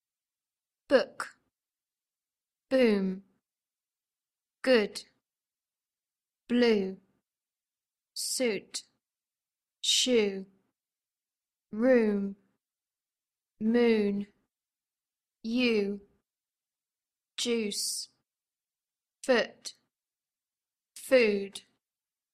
Phonetics
In this unit we are going to learn how to pronounce "o" and "u" in English. Look at these symbols that represent them and then listen to the pronunciation of the words in each chart: